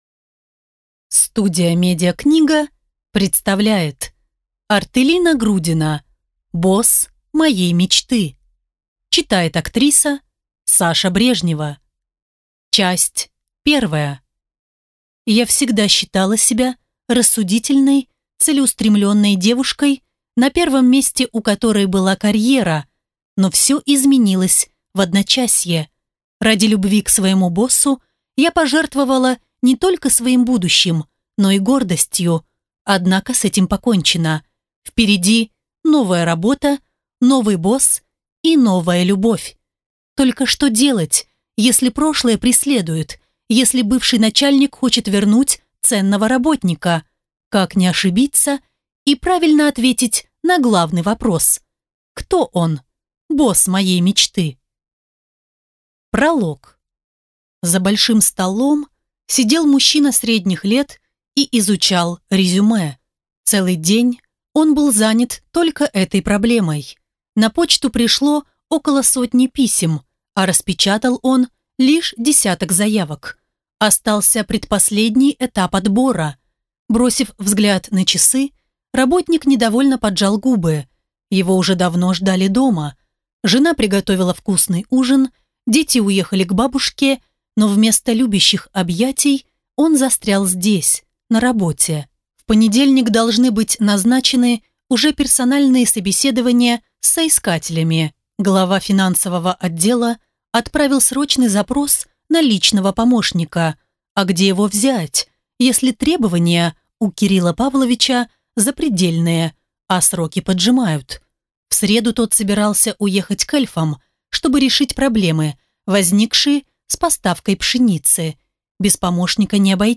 Аудиокнига Босс моей мечты. Книга 1 | Библиотека аудиокниг